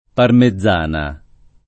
[ parme zz# na ]